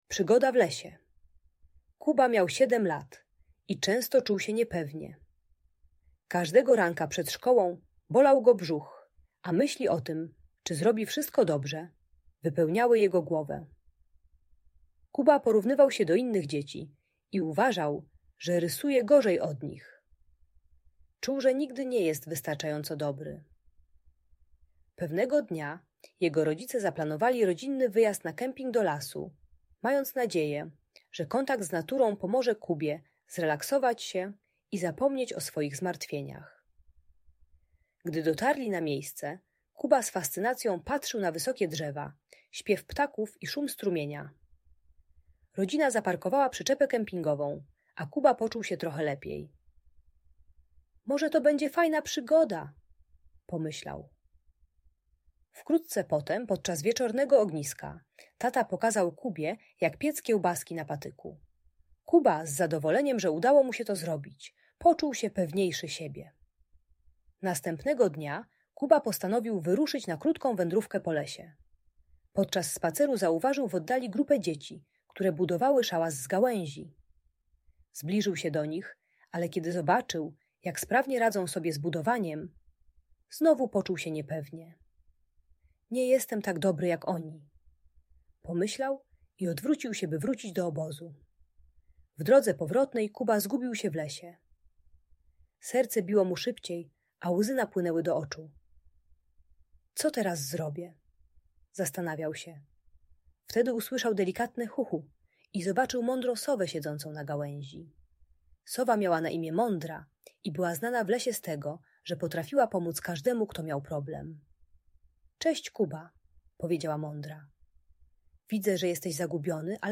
Przygoda w Lesie - Szkoła | Audiobajka
Uczy techniki doceniania małych sukcesów i odkrywania własnych talentów. Audiobajka o lęku przed szkołą i braku pewności siebie dla pierwszoklasisty.